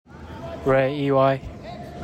Pronunciaton: RAY E Y